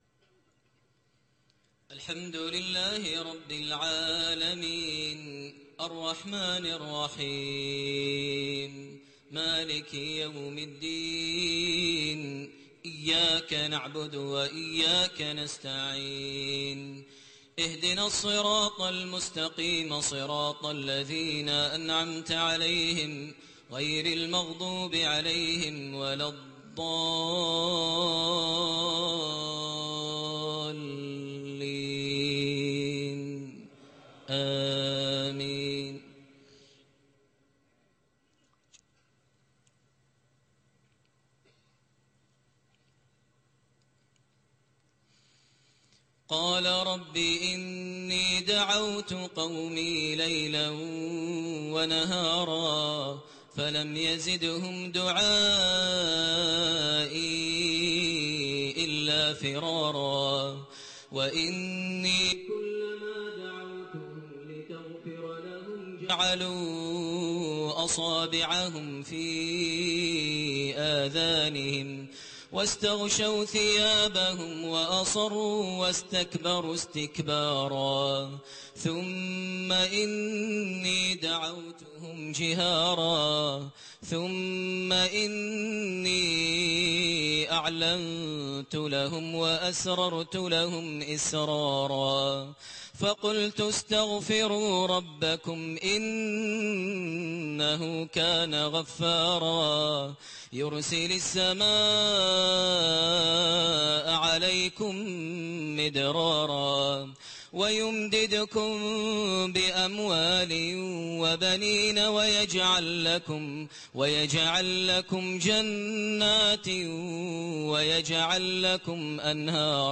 Maghrib prayer from Surat Nooh > 1429 H > Prayers - Maher Almuaiqly Recitations